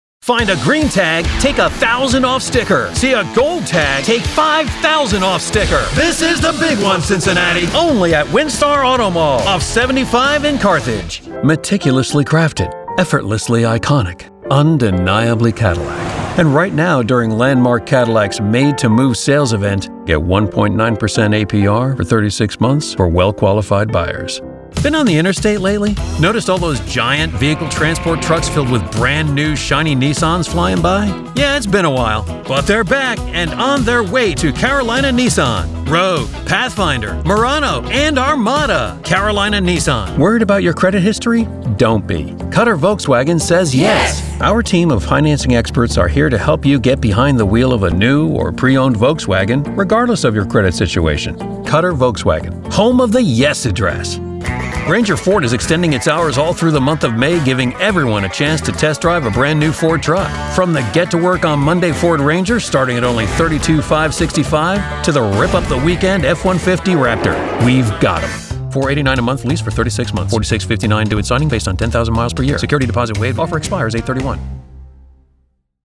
Automotive Demo